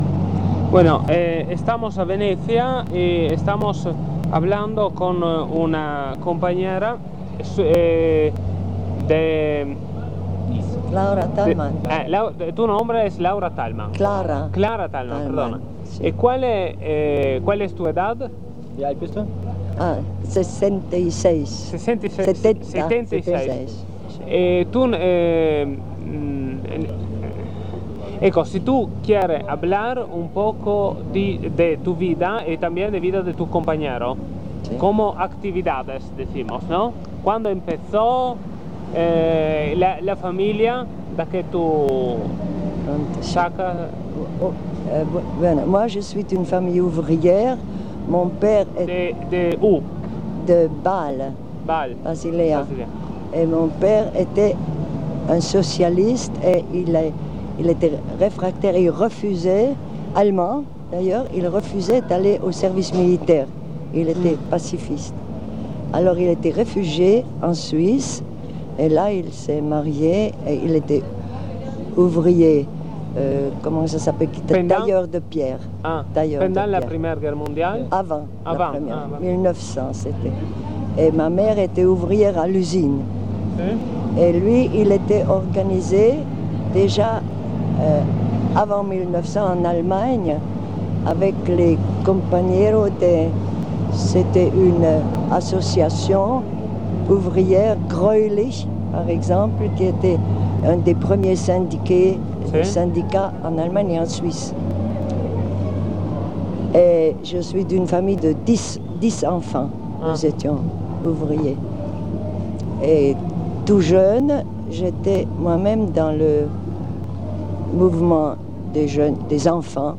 File Audio dell'intervista